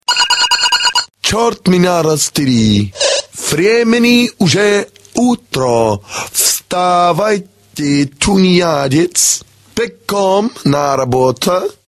/64kbps) Описание: На будильник. Вас будит эстонец.